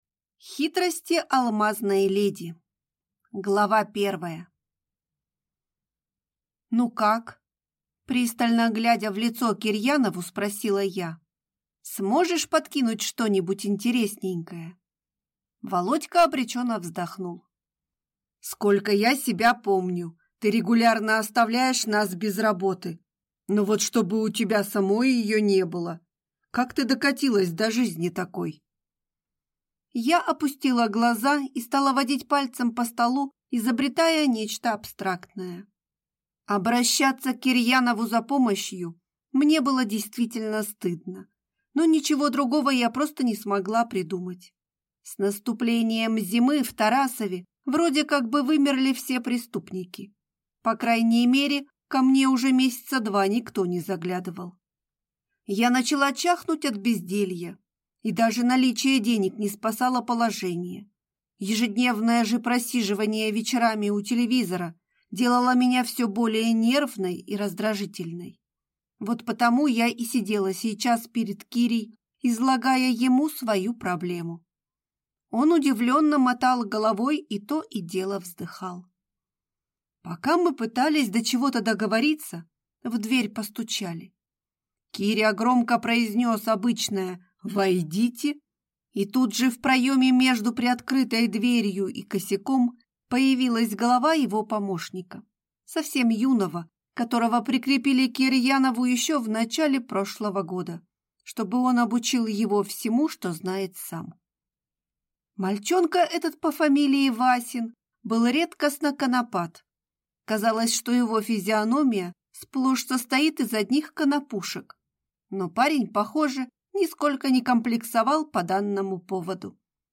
Аудиокнига Хитрости алмазной леди | Библиотека аудиокниг